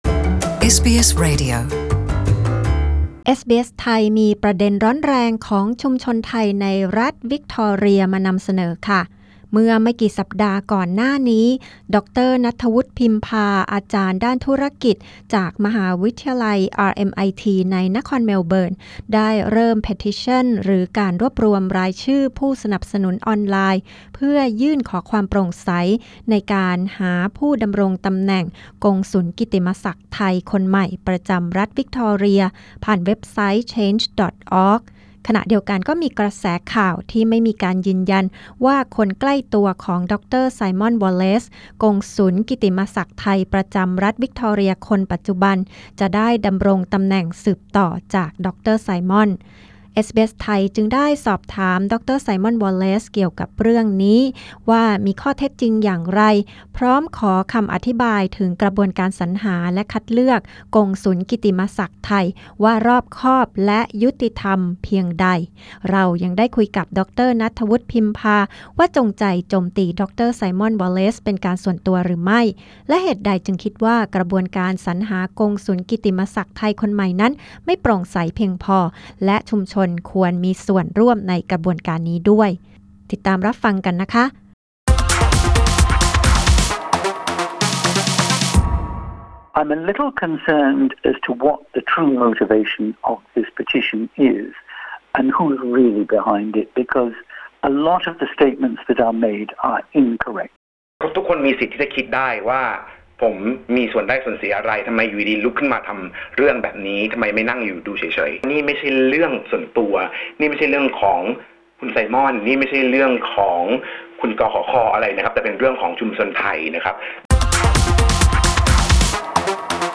พอดคาสต์นี้ประกอบด้วยบทสัมภาษณ์